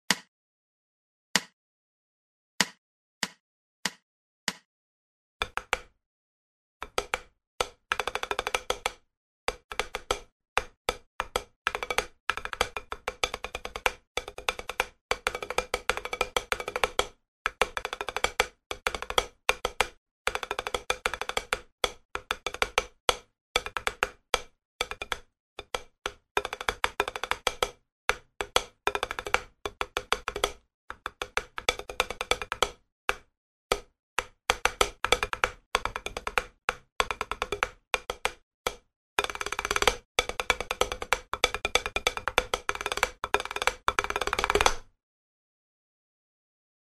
Все этюды записаны на педе для большей разборчивости и возможно помогут тем кто занимается по указанной книге самостоятельно.
Этюд №28 - содержит рудименты некратных и кратных роллов.
Размер 4/4, темп - 96 bpm.